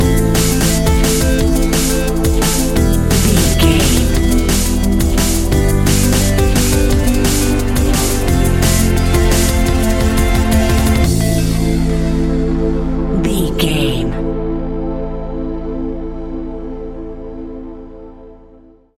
royalty free music
Aeolian/Minor
Fast
futuristic
hypnotic
industrial
dreamy
frantic
aggressive
powerful
synthesiser
drums
electronic
sub bass